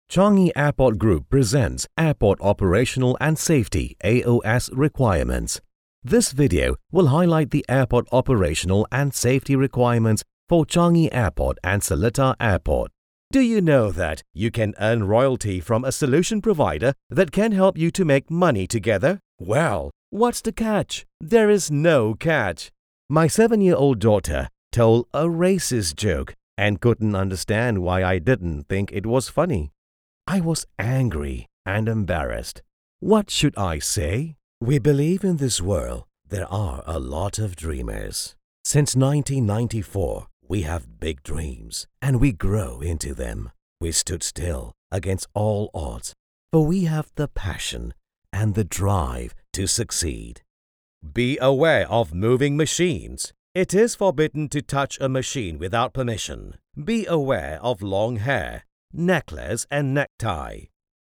Masculino
VO Reel Malaysian
Deep Matured voice who has won numerous awards such as the EFFIE awards in 2007.